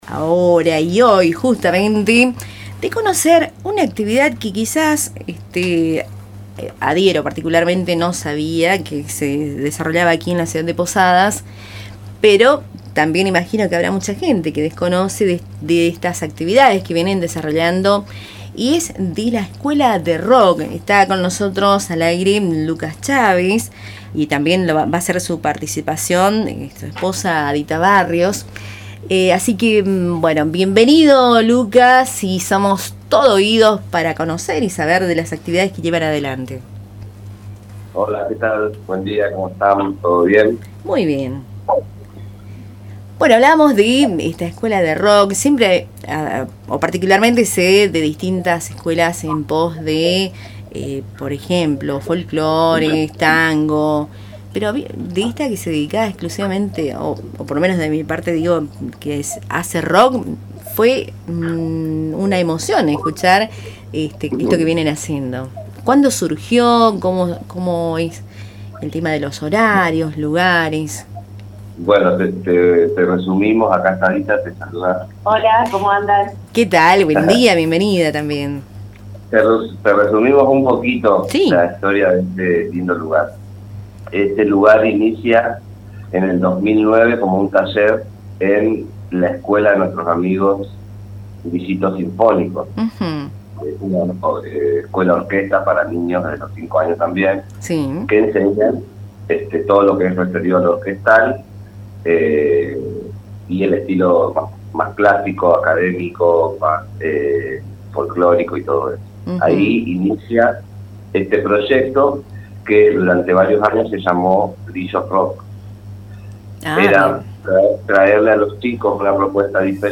Escuchá la entrevista completa, realizada en Tupambaé: https